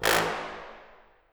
Fat_Horn_6.wav